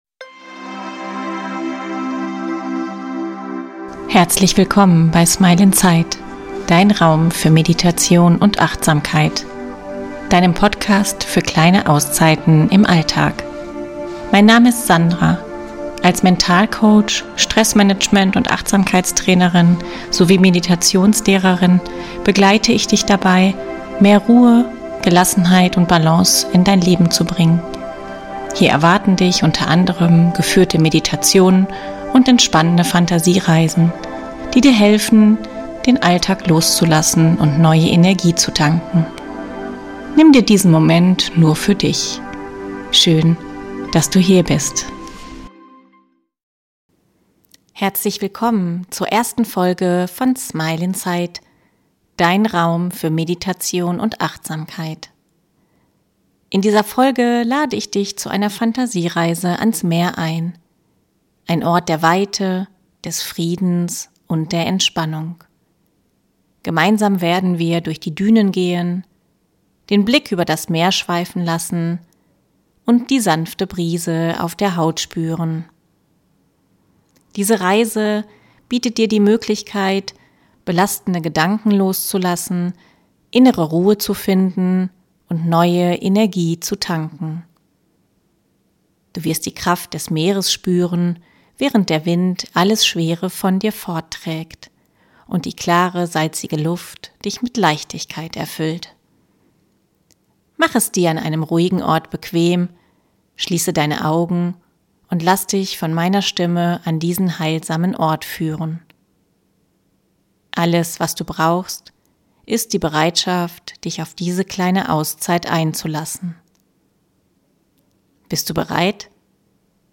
Mach es dir an einem ruhigen Ort bequem, schließe deine Augen und lass dich von meiner Stimme an diesen heilsamen Ort führen.